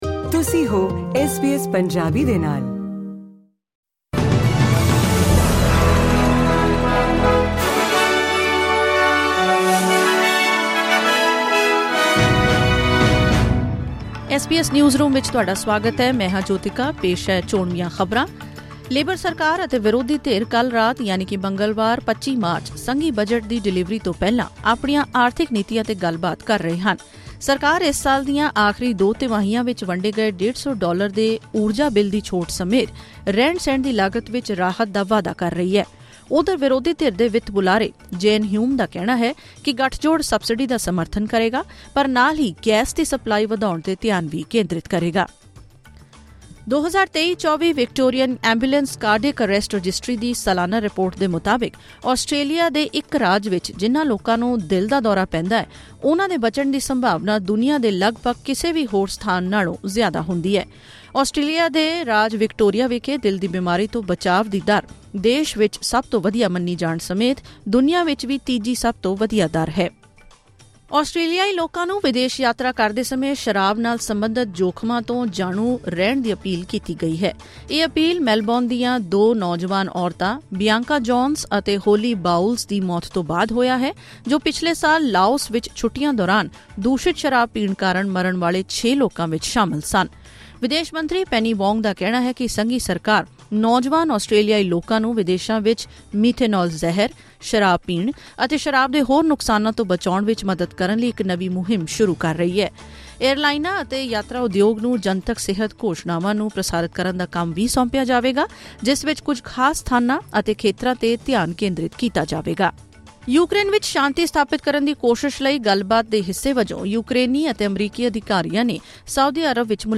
ਖ਼ਬਰਨਾਮਾ: 25 ਮਾਰਚ ਨੂੰ ਸੰਘੀ ਬਜਟ ਦੀ ਡਿਲੀਵਰੀ ਤੋਂ ਪਹਿਲਾਂ ਲੇਬਰ ਸਰਕਾਰ ਅਤੇ ਵਿਰੋਧੀ ਧਿਰ ਵੱਲੋਂ ਆਰਥਿਕ ਨੀਤੀਆਂ 'ਤੇ ਵਿਚਾਰ